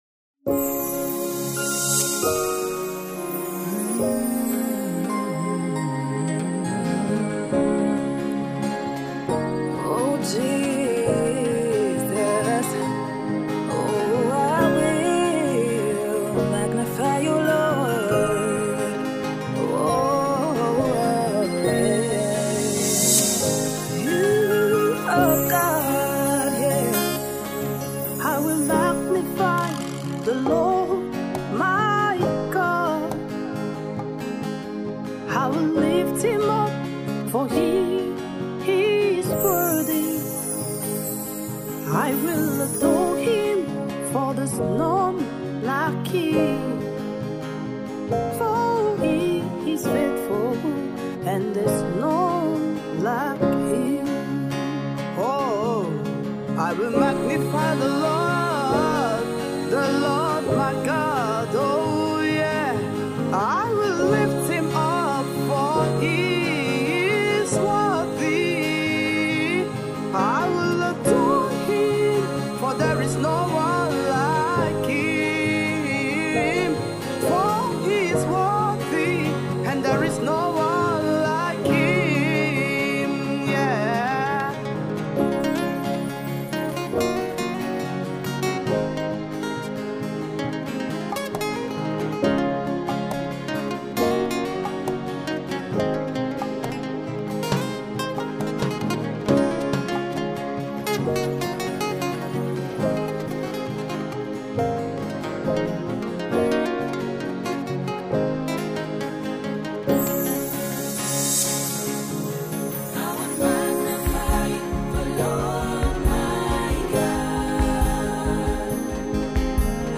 Nigerian Port-harcourt based vibrant gospel songstress
heart-felt worship